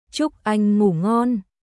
Chúc anh ngủ ngonチュック・アン・グー・ゴンおやすみ（恋人・年上の男性に）